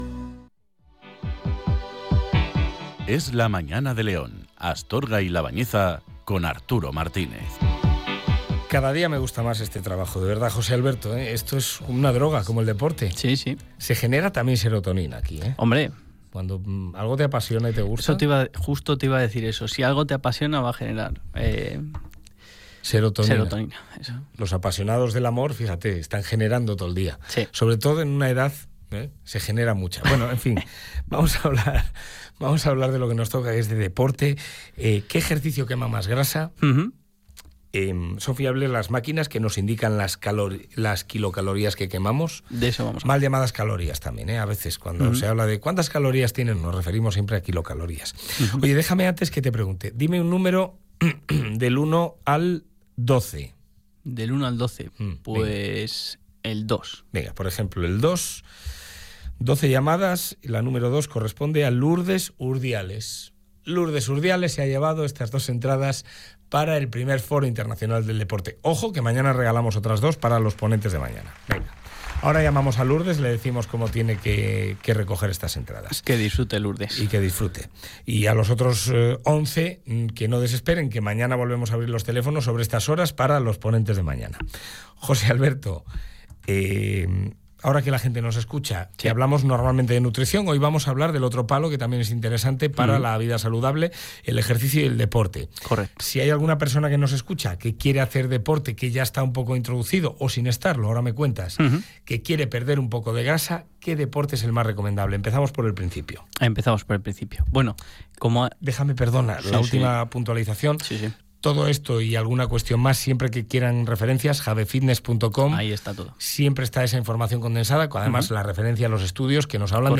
Hoy os traigo el centésimo décimo tercer programa de la sección que comenzamos en la radio local hace un tiempo y que hemos denominado Es Saludable.